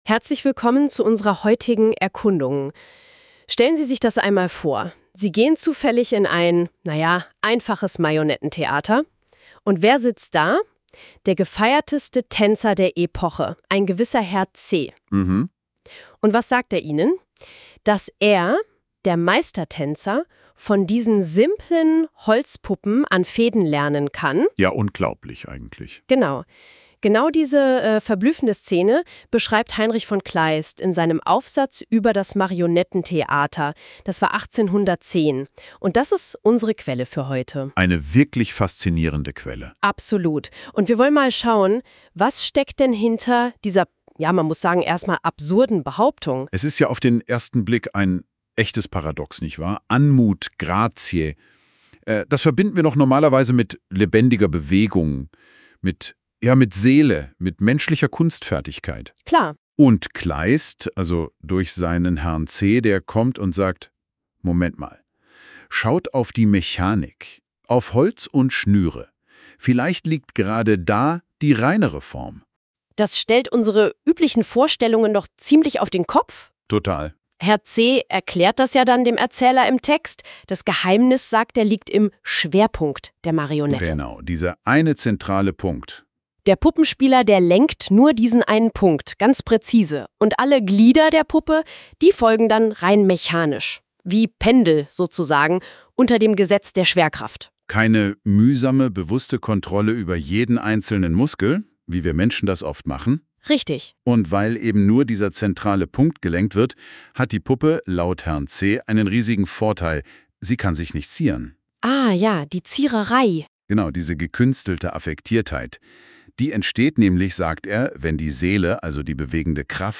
In unserer ersten Episode beschäftigen wir uns mit Heinrich von Kleist. Wir debattieren über seine Texte über die Verfertigung der Gedanken beim Reden sowie das Marionettentheater, lassen uns einen dieser Texte komplett vorlesen und schauen zu guter letzt noch auf eine wissenschaftliche Arbeit, die sich mit der Improvisation in Heinrich von Kleists Werk auseinandersetzt.